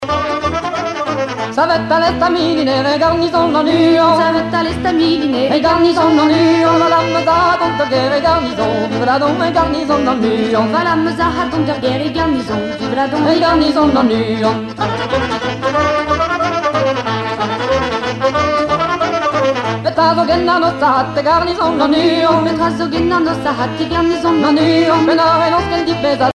danse : plinn
Pièce musicale éditée